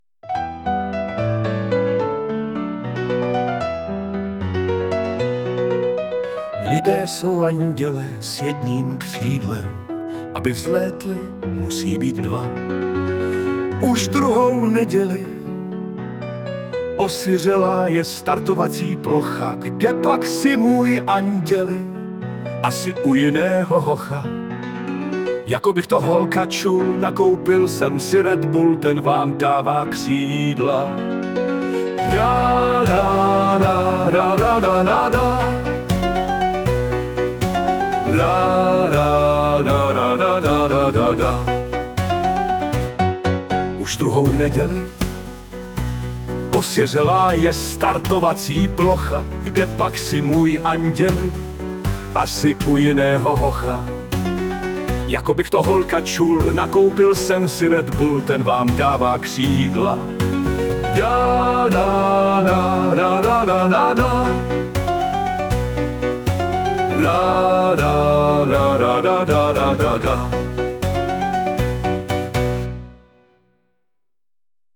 * hudba, zpěv, obr.: AI